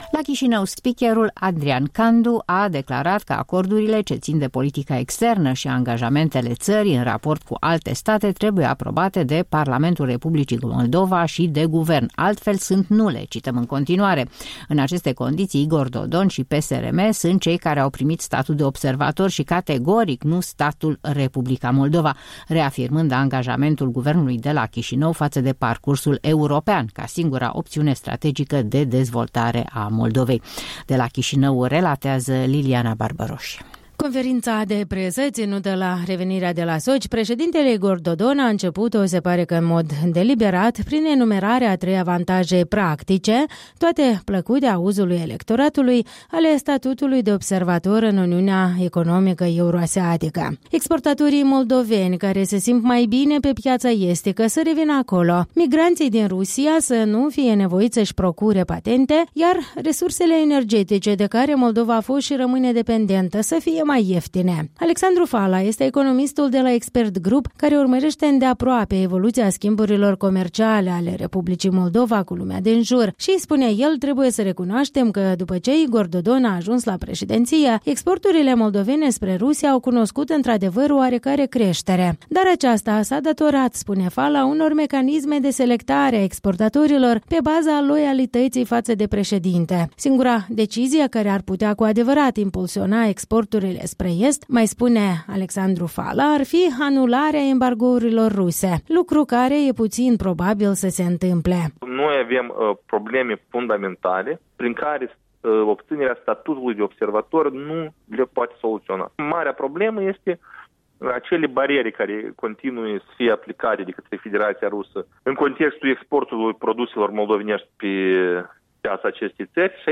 Corespondenta noastră a stat de vorbă cu trei experţi ca să afle dacă e acesta un demers realist?